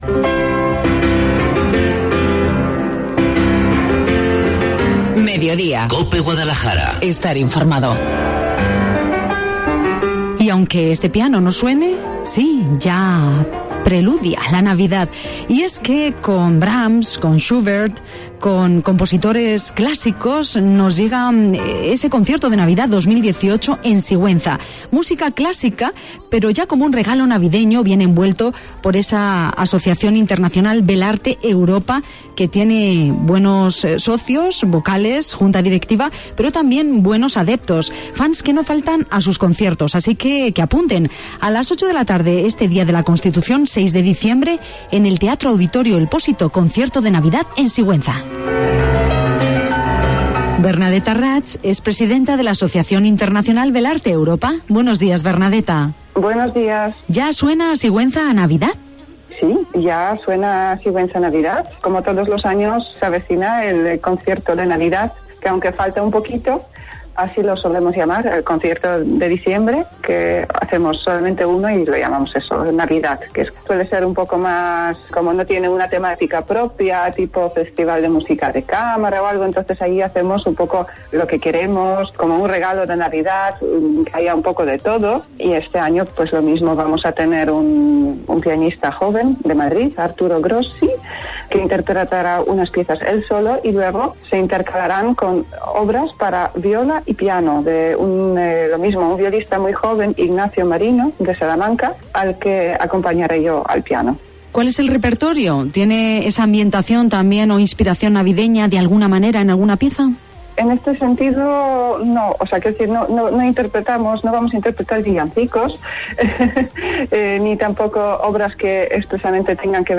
En Cope Guadalajara, hemos hablado